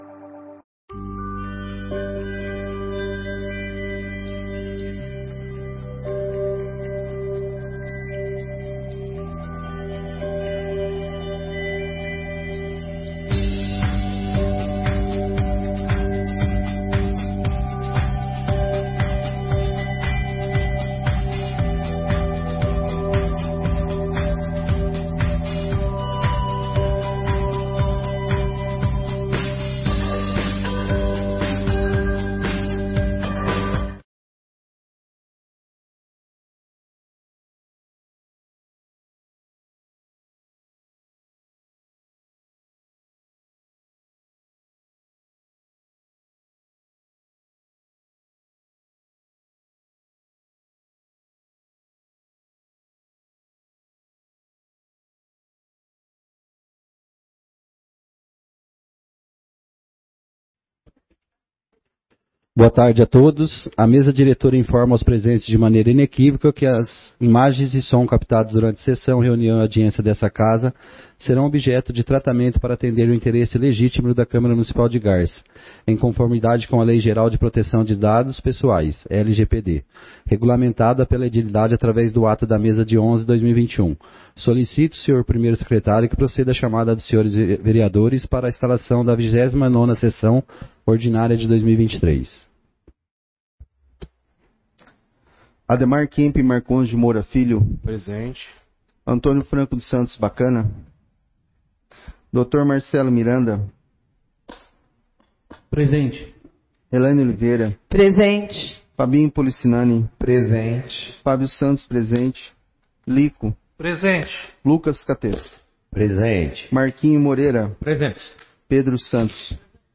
29ª Sessão Ordinária de 2023